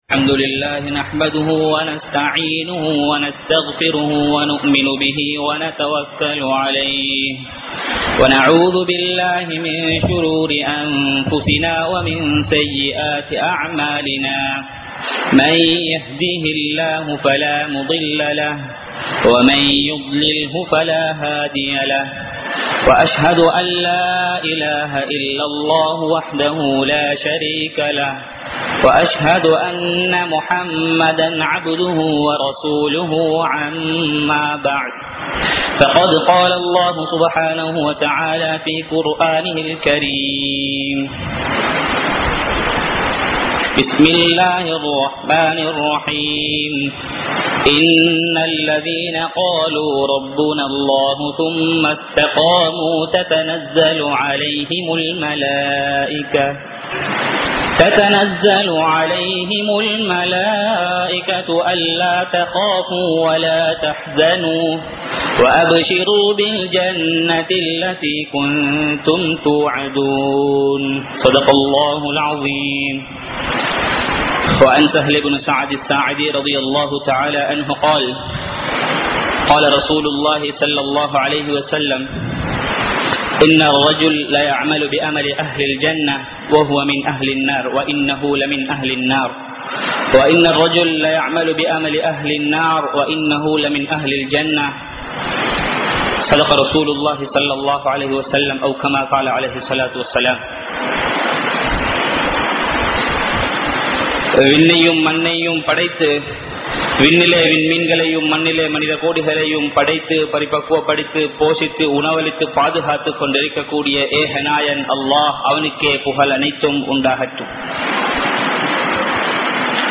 Iruthi Mudivai Payanthu kollungal (இறுதி முடிவை பயந்து கொள்ளுங்கள்) | Audio Bayans | All Ceylon Muslim Youth Community | Addalaichenai